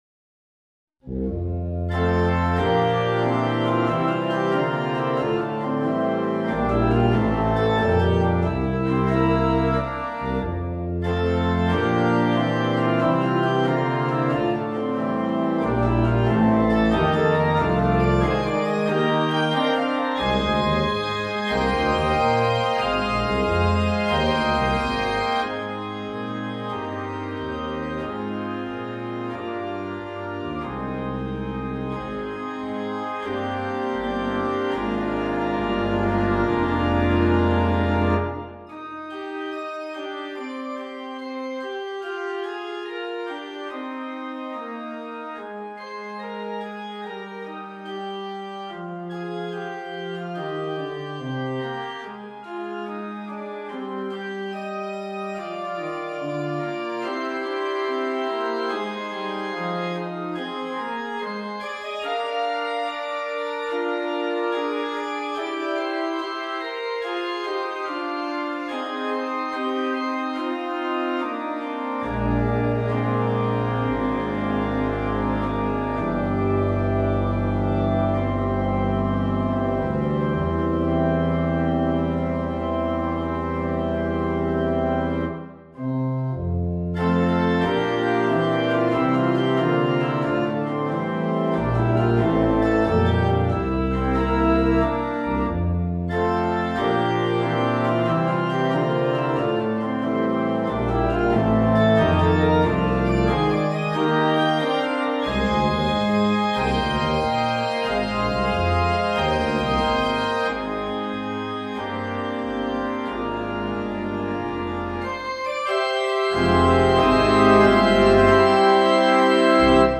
Organ…